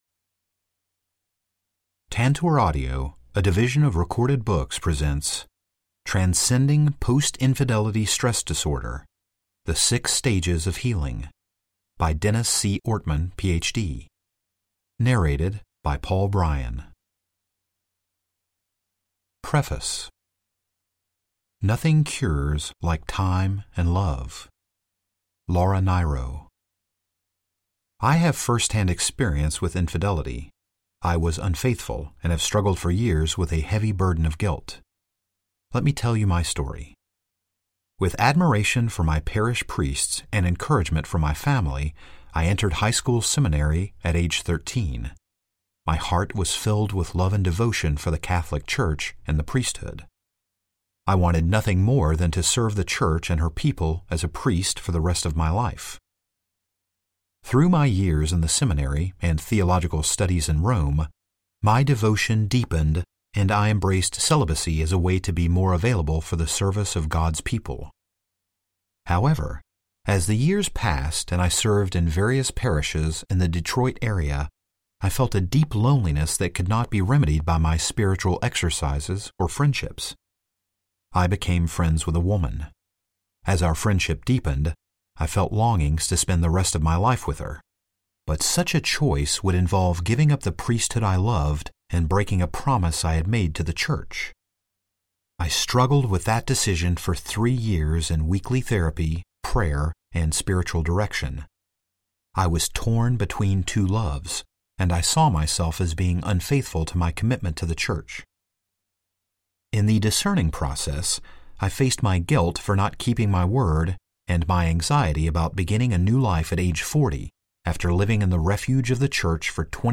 Narrator
9.2 Hrs. – Unabridged